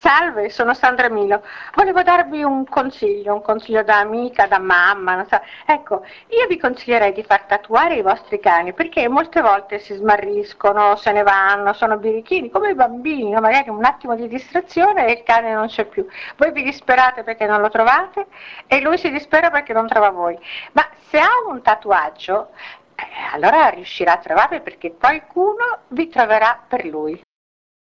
ASCOLTA GLI SPOT DI SANDRA MILO